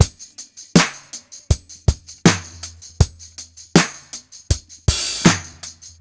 • 80 Bpm Drum Beat E Key.wav
Free breakbeat - kick tuned to the E note. Loudest frequency: 5775Hz
80-bpm-drum-beat-e-key-TMS.wav